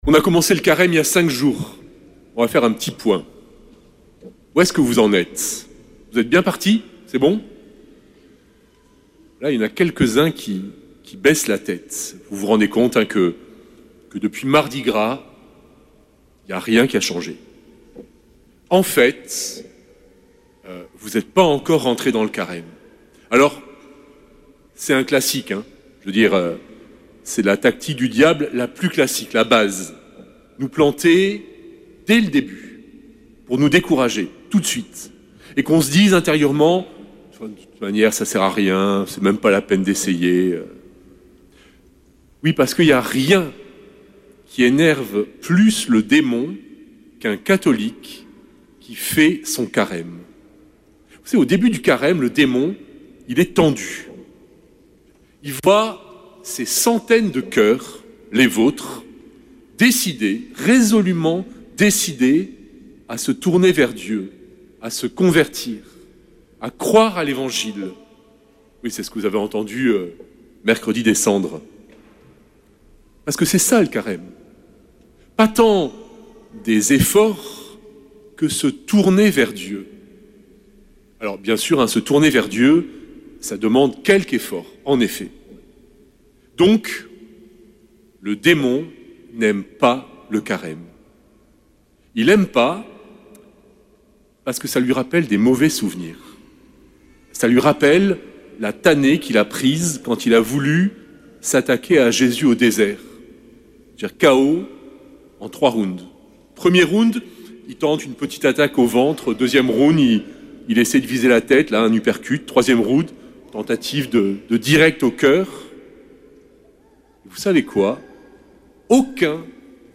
Messe depuis le couvent des Dominicains de Toulouse
Homélie